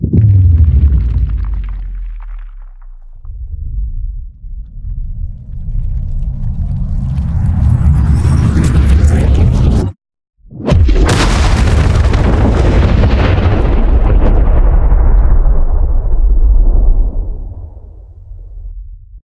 shatterpoint_blowout.ogg